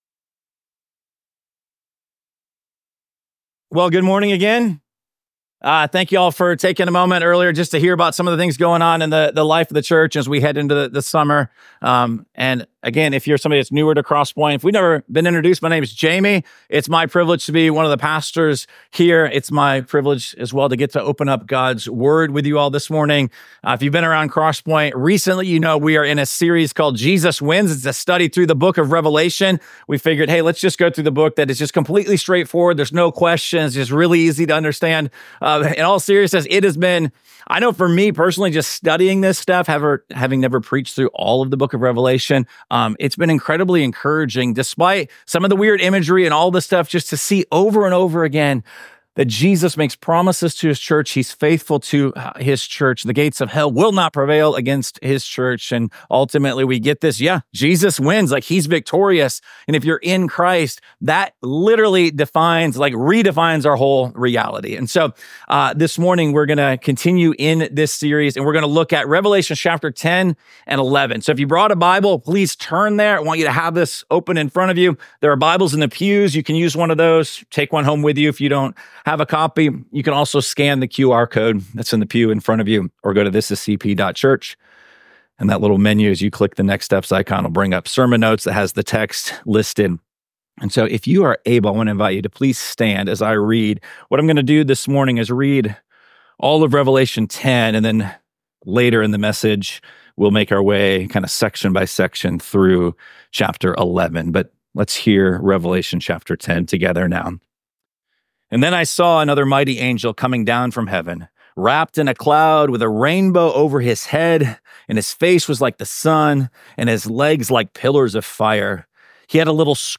Week 7 of our series Jesus Wins: A Study of Revelation. This sermon comes from Revelation chapters 10-11.